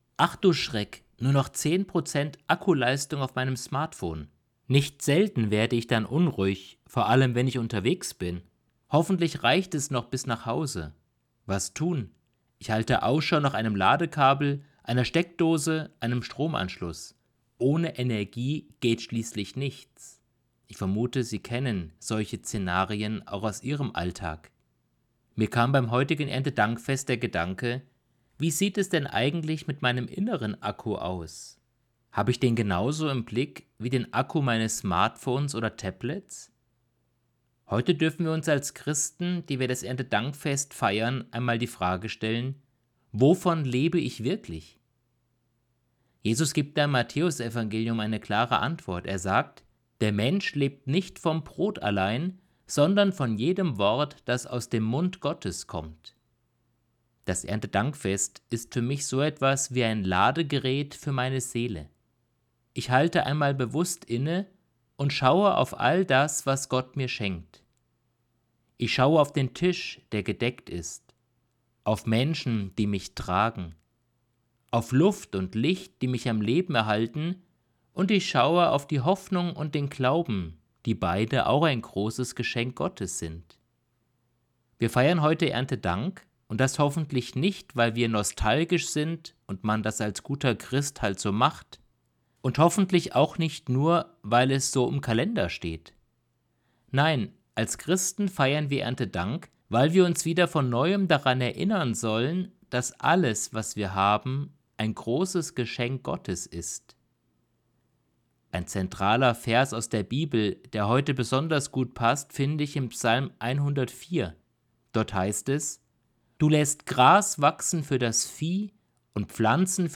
Predigt vom 05.10.2025